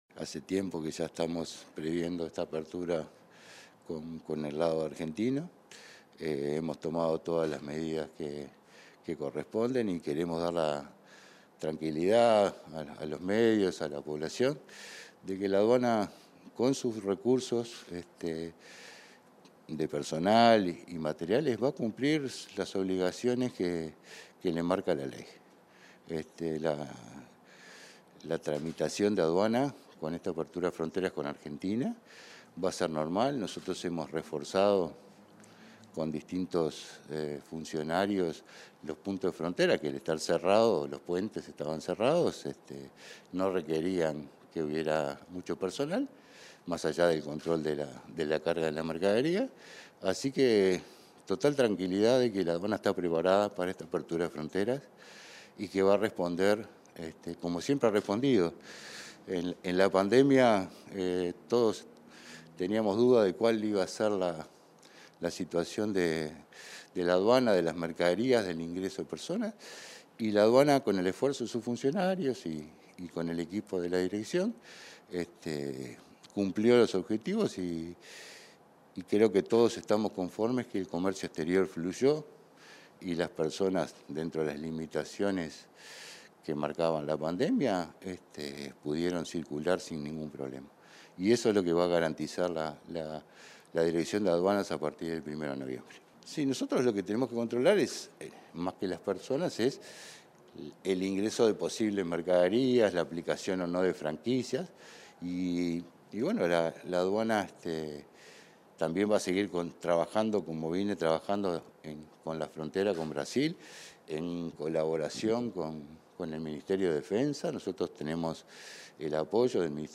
Entrevista al director nacional de Aduanas, Jaime Borgiani